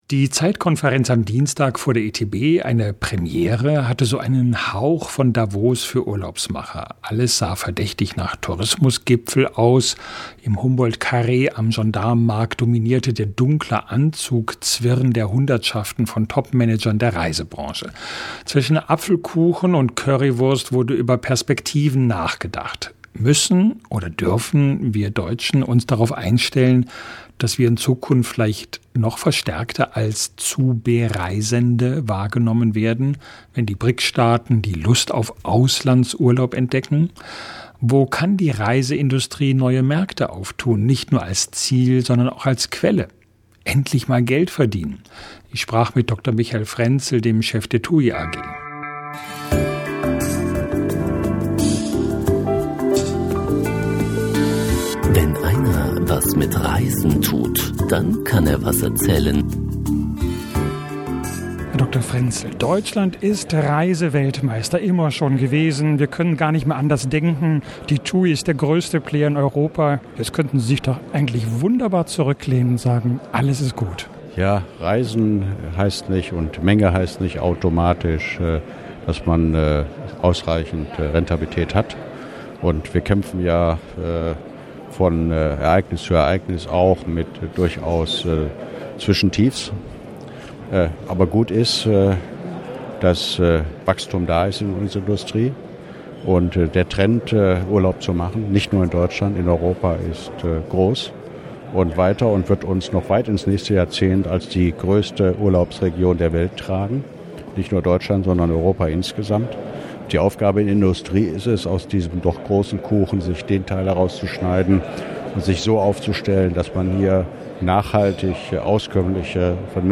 Direktlink: Gespräch mit Dr. Michael Frenzel
Reiseradio_094_Interview_Michael-Frenzel_komplett.mp3